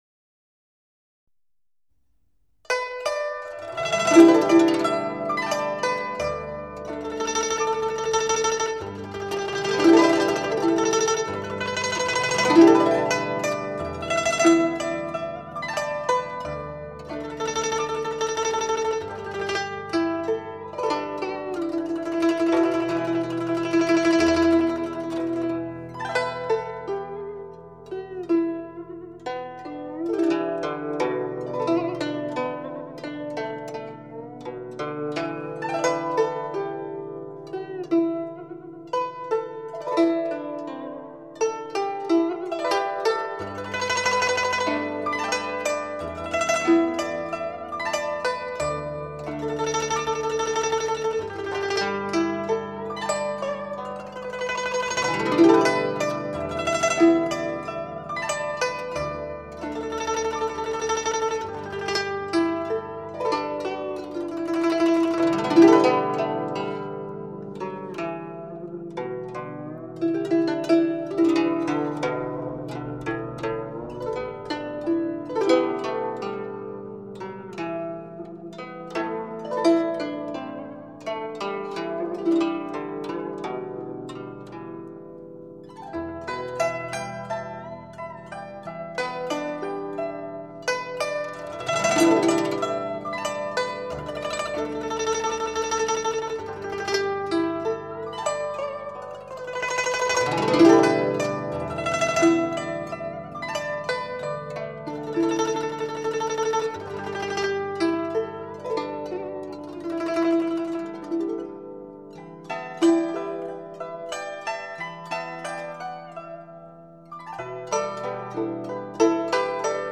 发烧新概念 模拟纯声音 真实无化装
声音有血有肉，而且定位准确，
每件乐器的定位都交代得不错，
而且这张唱片的录音的平衡度把握得很好，